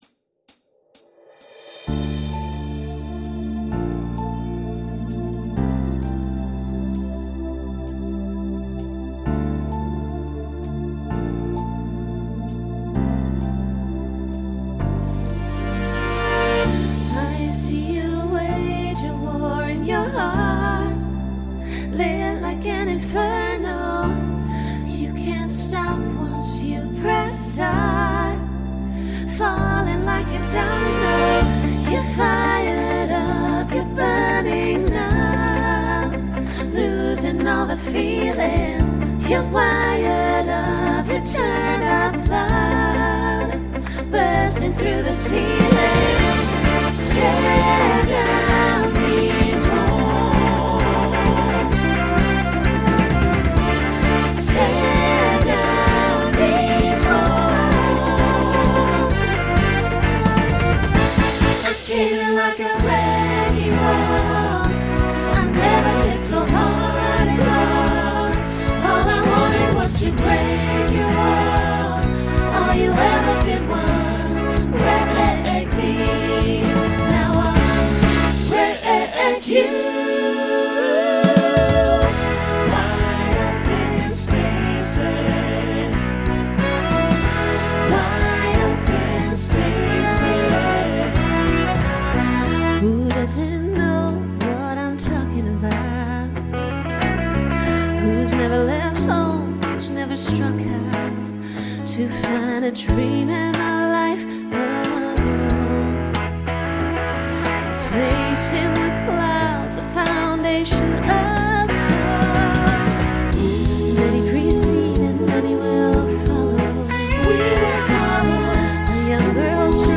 Utilizes a small group to facilitate costume change.
Country , Pop/Dance
Voicing Mixed